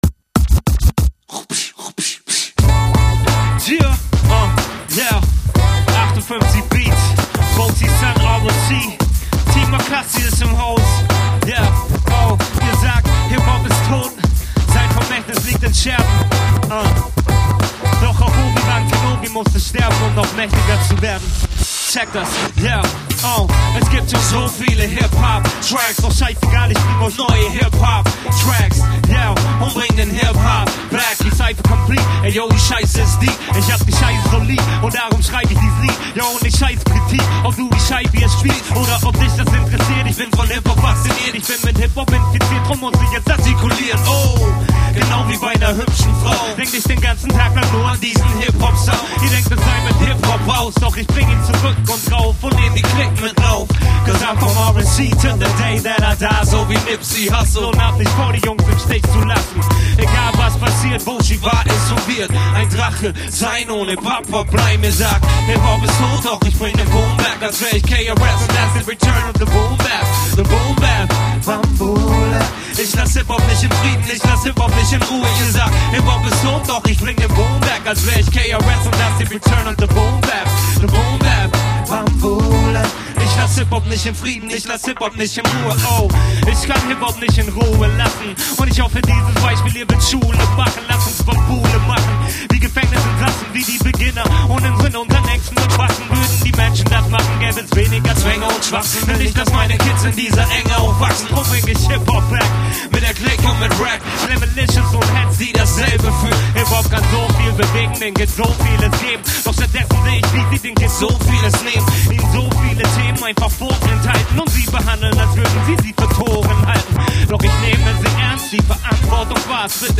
live eingespielt im on3-Studio.